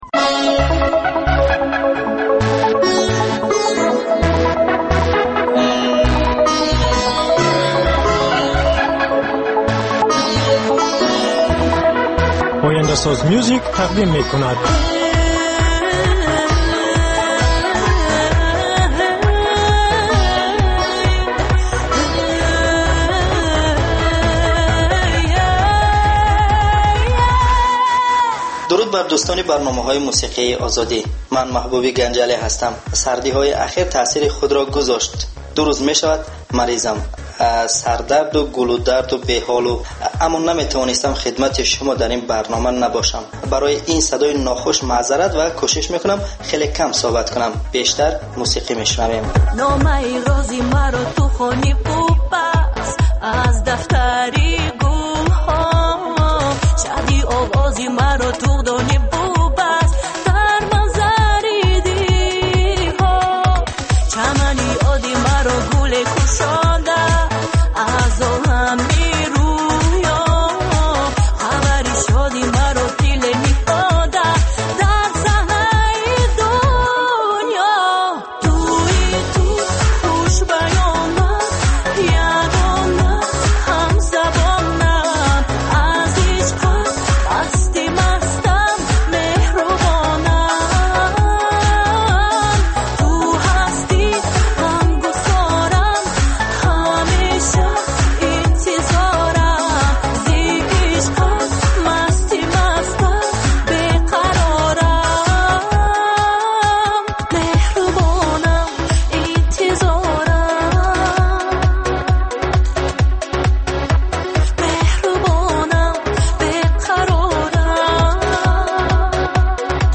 Барномаи мусиқӣ